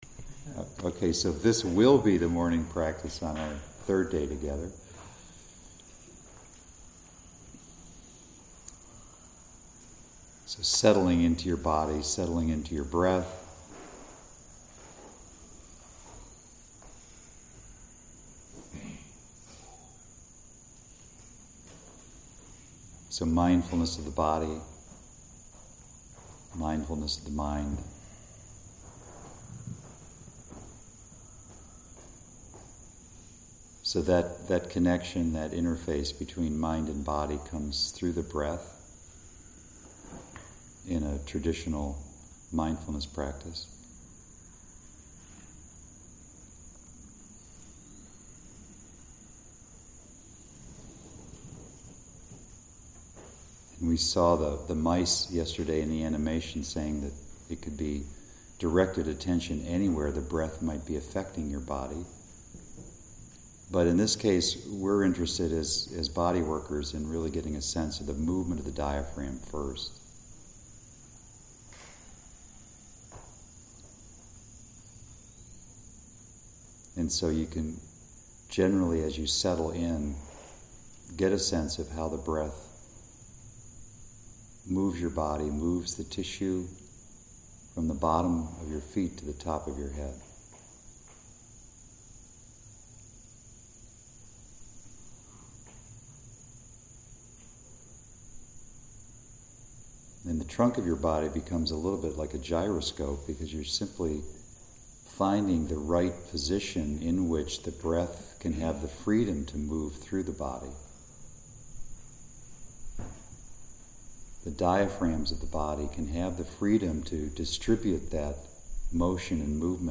Audio Lectures
meditation_on_merging_with_nature.mp3